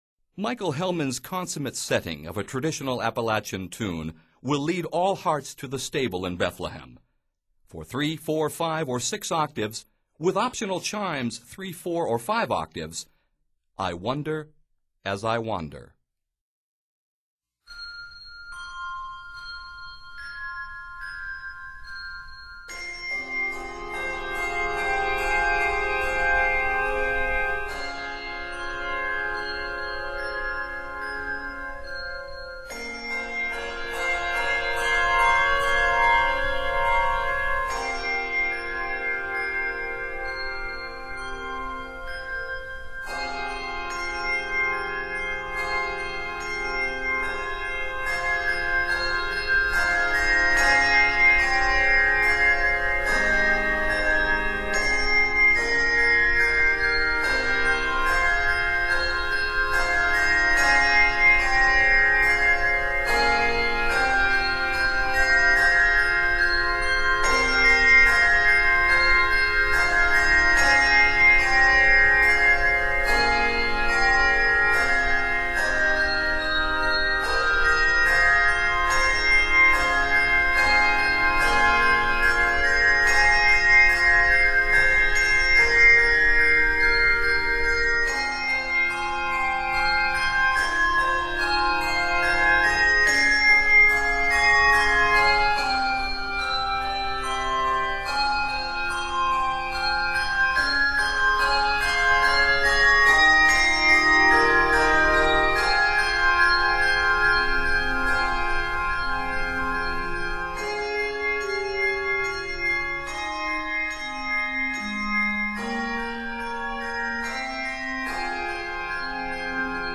It is arranged in f minor.